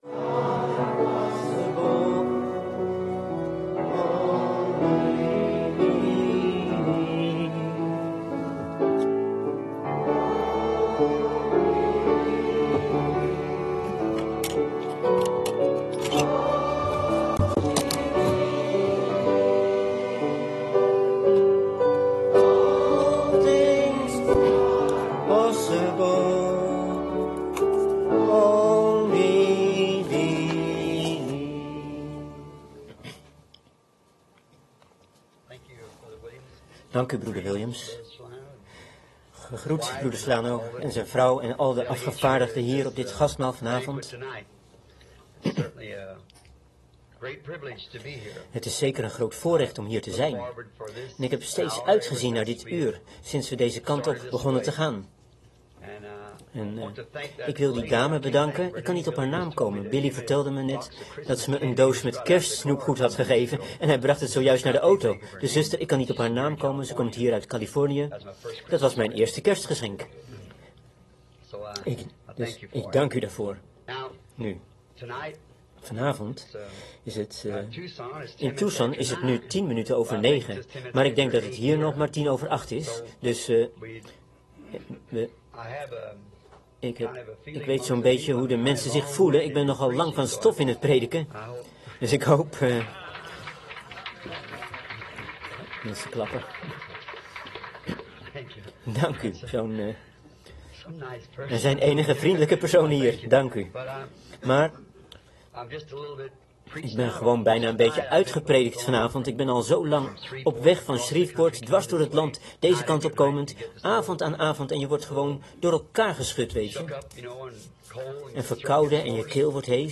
De vertaalde prediking "Leadership" door William Marrion Branham gehouden in Covina Bowl, Covina, California, USA, 's avonds op dinsdag 07 december 1965